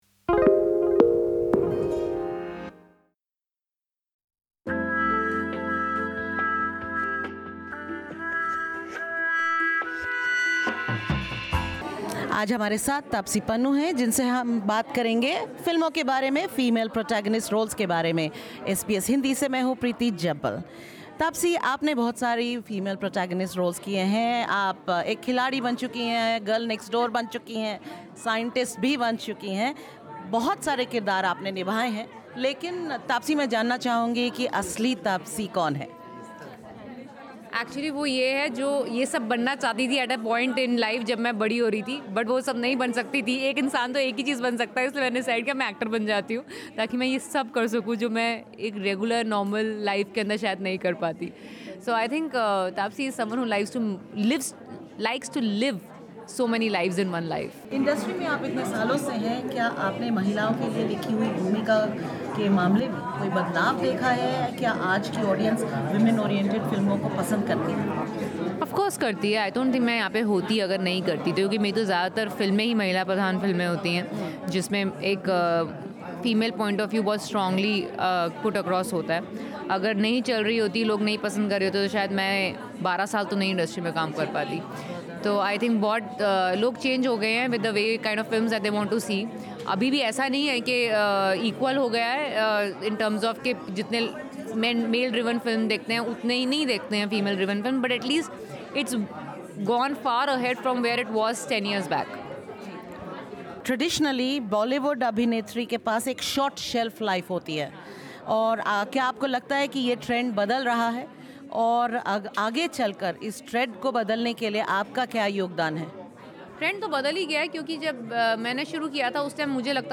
Taapsee Pannu, an Indian actress and guest at the Indian Film Festival of Melbourne 2022, speaks candidly with SBS Hindi about her career in showbiz, female protagonist roles, boycott Bollywood trends, and the cost of success she does not wish to pay.